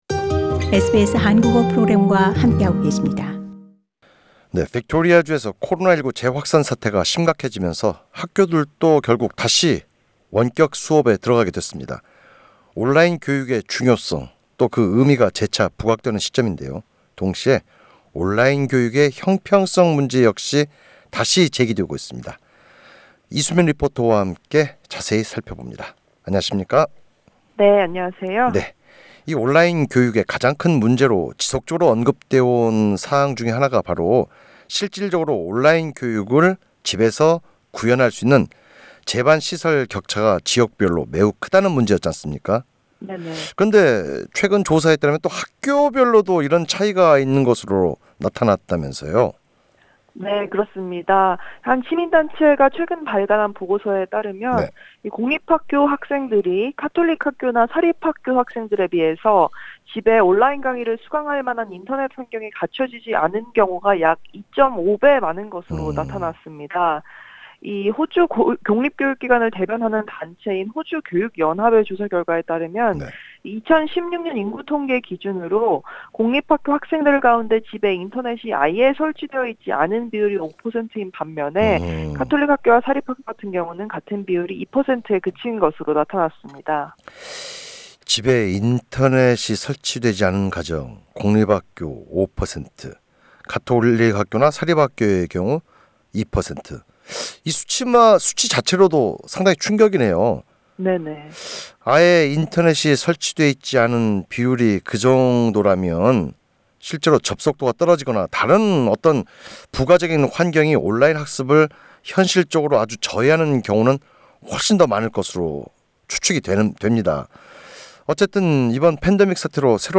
리포터: 네 그렇습니다.